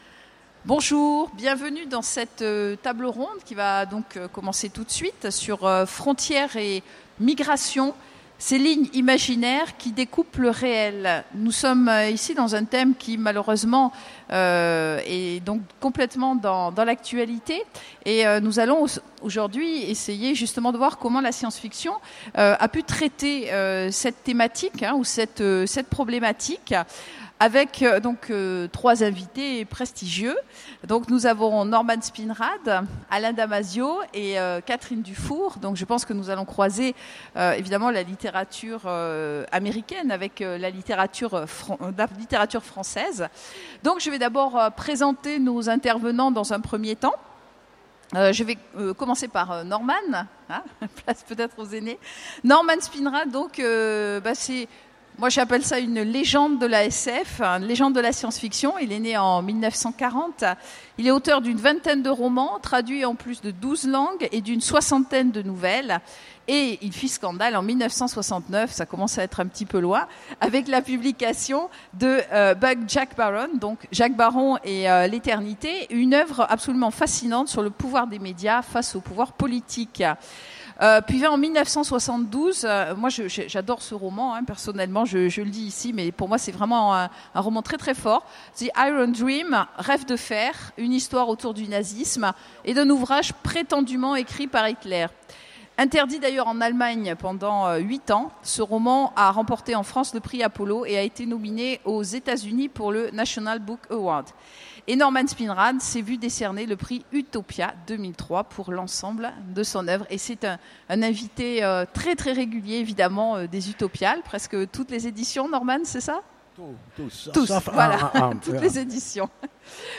Utopiales 2015 : Conférence Frontières et migrations